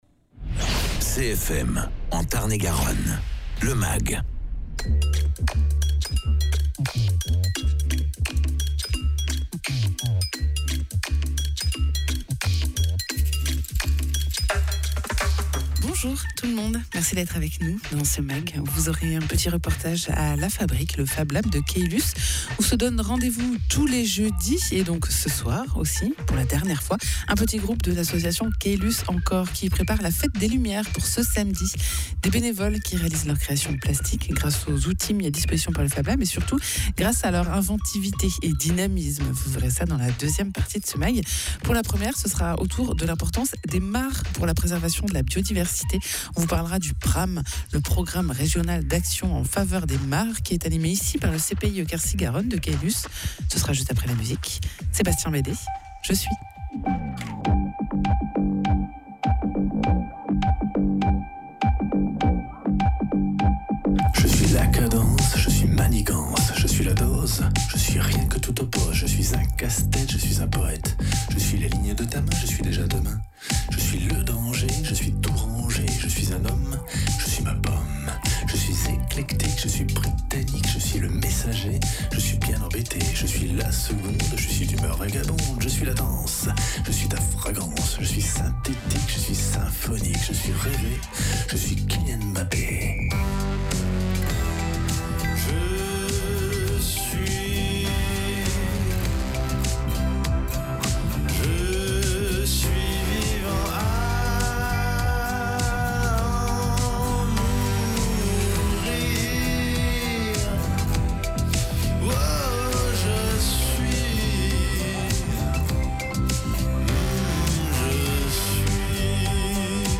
Où on parle de l’importance des mares, de leur préservation et de la participation du CPIE Quercy Garonne de Caylus au PRAM : le programme régional d’action en faveur des mares d’Occitanie. Egalement, une reportage à La Fabrique, fablab de Caylus, où sont confectionnés des installations lumineuses par les bénévoles de l’association Caylus enCor pour la fête des lumières de samedi.